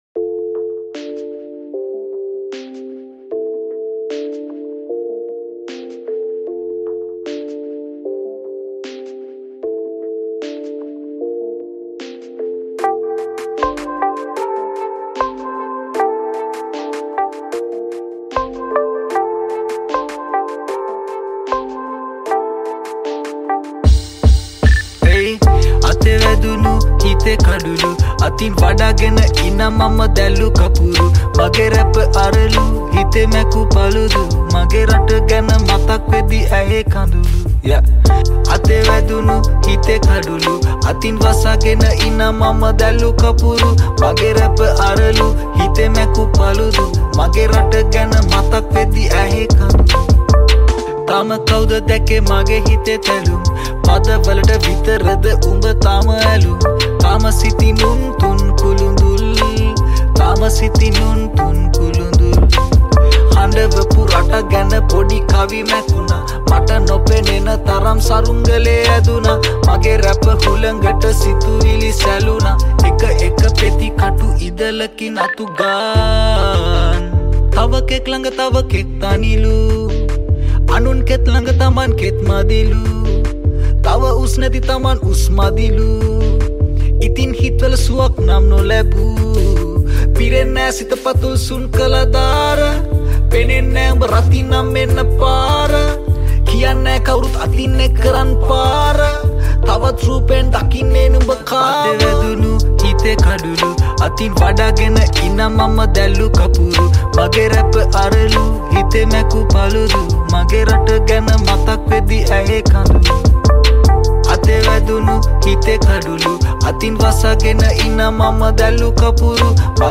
High quality Sri Lankan remix MP3 (3.4).
Rap